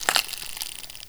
ALIEN_Insect_15_mono.wav